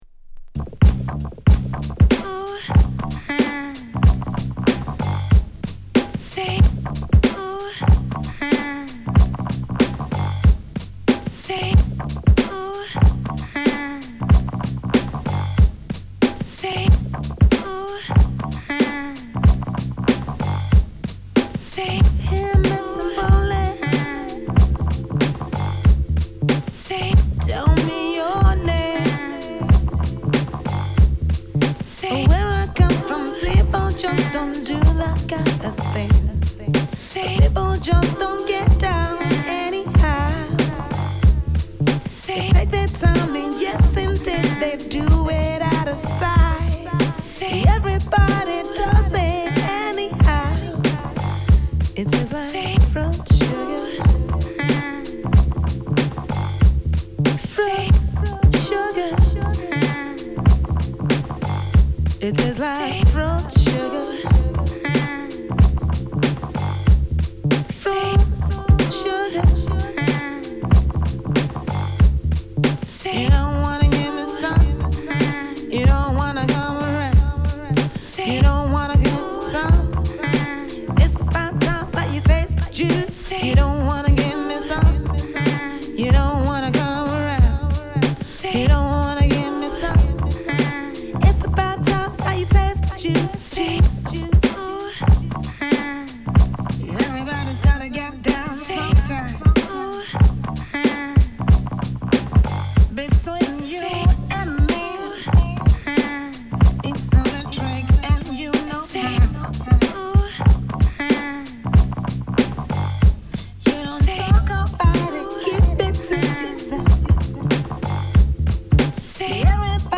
ナイトクルージン。。。